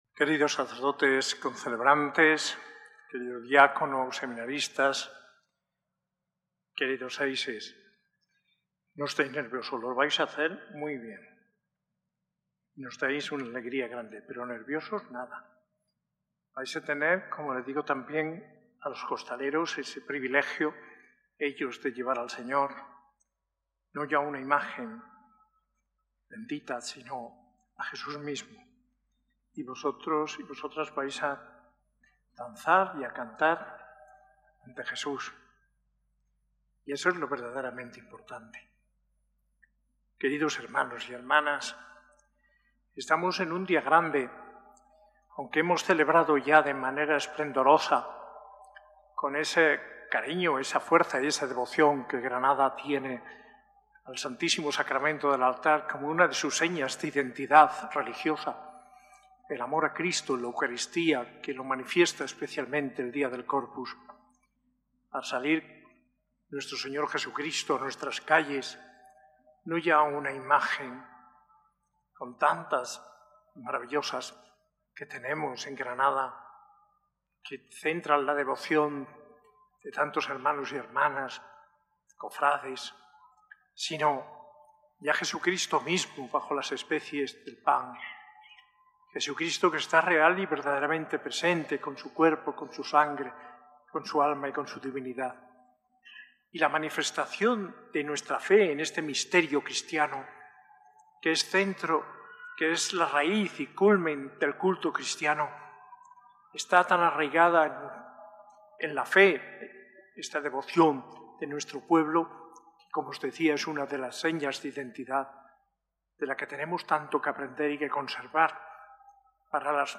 Homilía en la solemnidad del Corpus Christi de manos del arzobispo Mons. José María Gil Tamayo, celebrada en la S.A.I Catedral el 22 de junio de 2025.